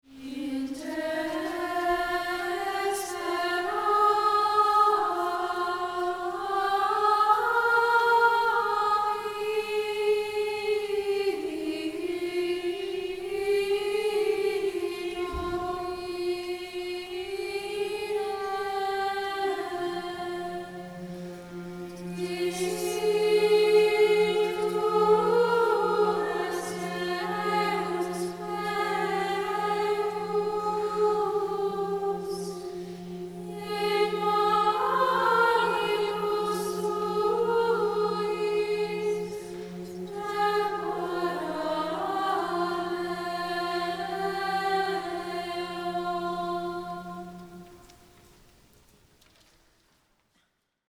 In Example #1 (“In te sperávi, Dómine”), we used a single drone line.
For this example, the women began the chant and then led the men naturally into their drone on “Re.” This staggered entrance creates a smooth transition and makes it easy for the singers to lock into their sustaining tone without hesitation.
Mp3 Download • Live Rec. (Single organum drone)
Ave Maria Parish Schola Cantorum